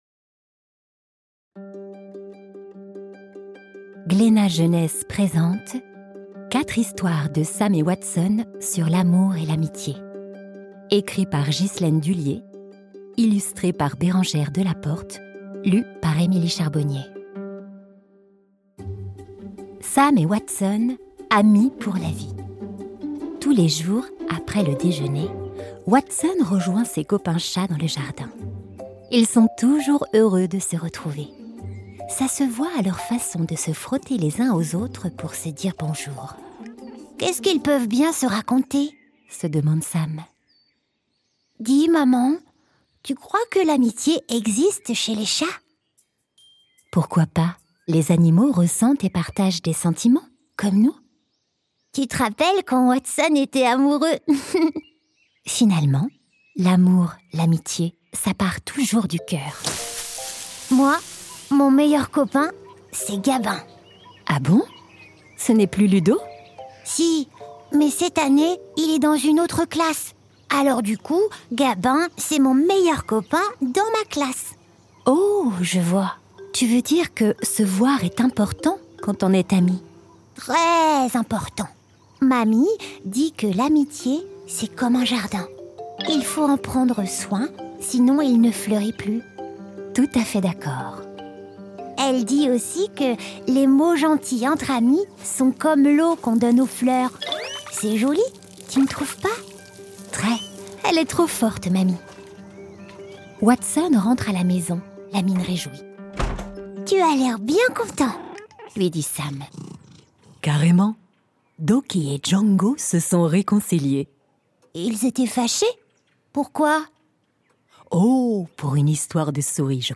*IH ou Interprétation Humaine signifie que des comédiennes et comédiens ont travaillé à l'enregistrement de ce livre audio, et qu'aucune voix n'a été enregistrée avec l'intelligence artificielle.